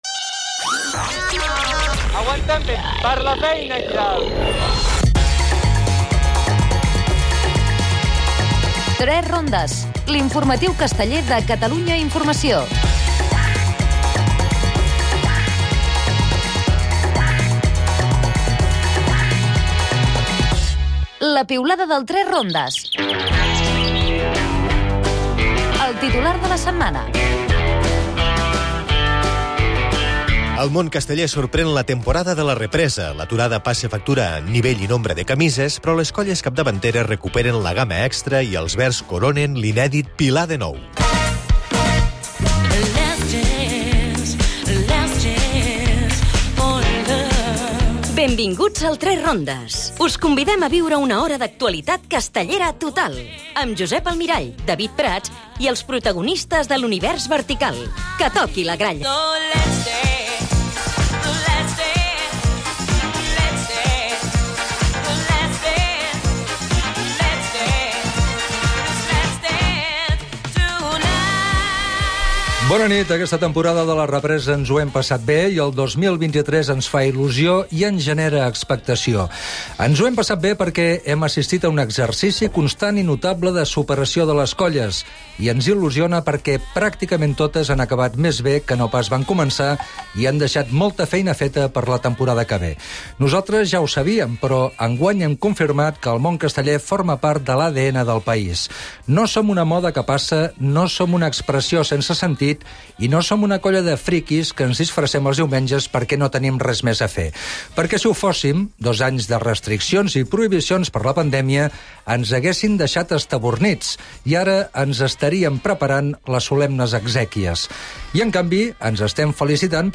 Al "3 rondes" hem estat a la diada del barri de Sant Andreu de Barcelona. Resum de les diades universitries.